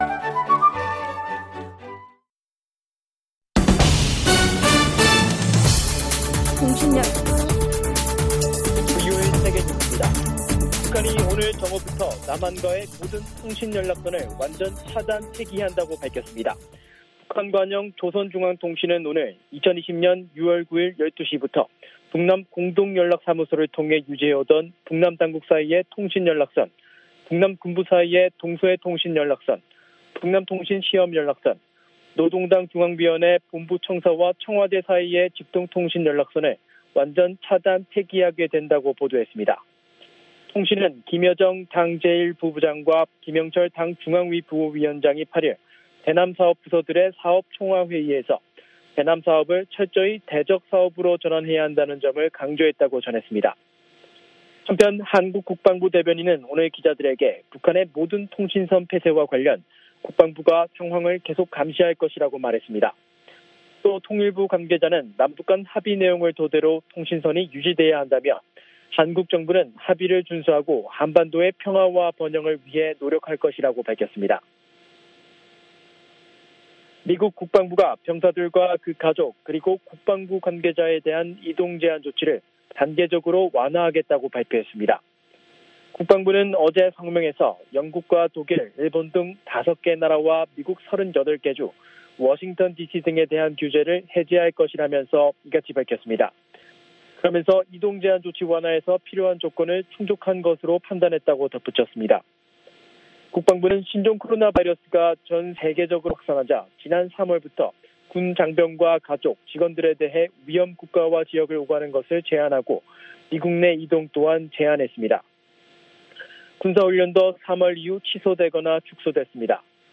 VOA 한국어 간판 뉴스 프로그램 '뉴스 투데이', 3부 방송입니다.